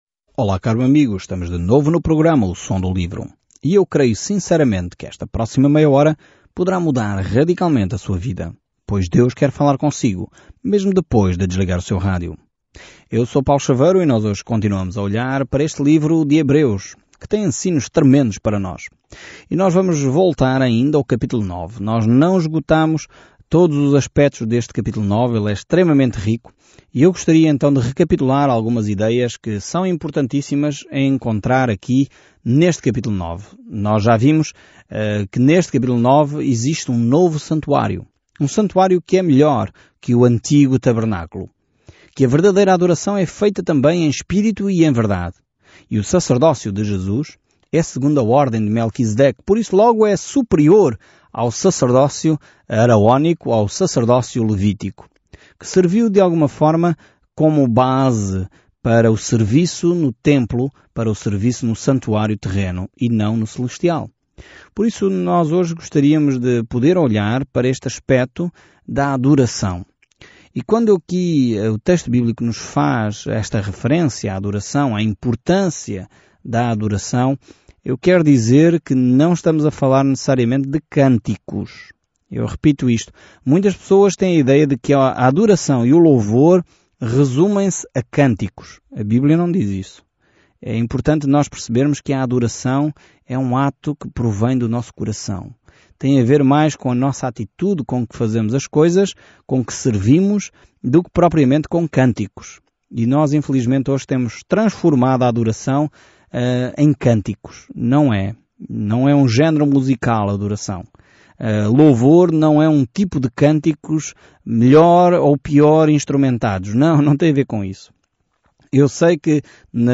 Viaje diariamente por Hebreus enquanto ouve o estudo em áudio e lê versículos selecionados da palavra de Deus.